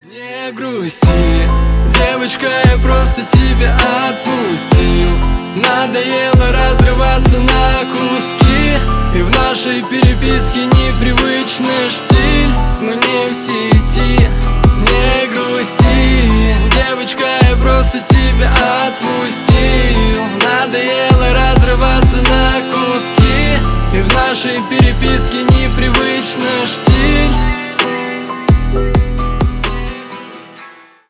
• Качество: 320 kbps, Stereo
Рэп и Хип Хоп
грустные